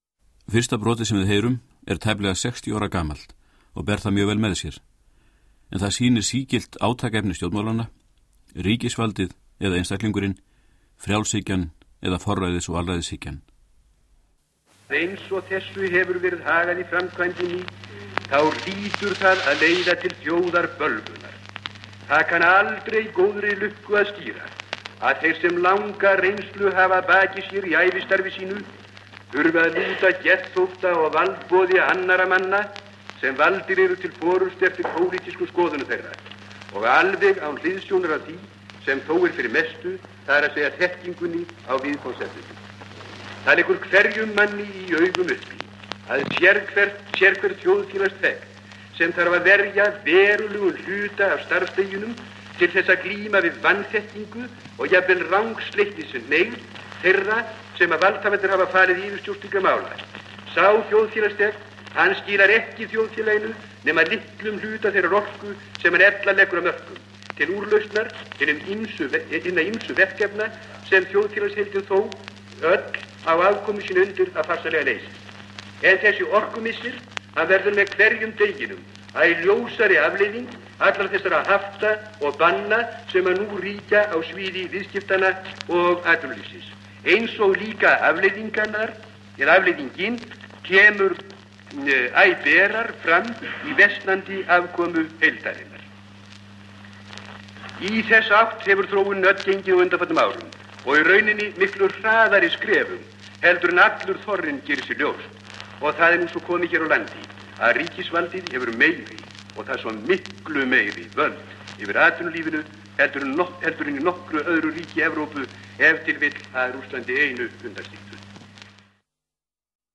Upptökurnar af ræðum Ólafs Thors voru fengnar hjá safndeild Ríkisútvarpsins með góðfúslegu leyfi stofnunarinnar.
Stjórnmálaumræður á Alþingi 1936.